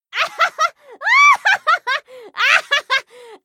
laughter_01